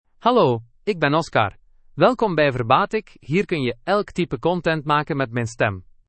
Oscar — Male Dutch (Belgium) AI Voice | TTS, Voice Cloning & Video | Verbatik AI
OscarMale Dutch AI voice
Oscar is a male AI voice for Dutch (Belgium).
Voice sample
Listen to Oscar's male Dutch voice.
Oscar delivers clear pronunciation with authentic Belgium Dutch intonation, making your content sound professionally produced.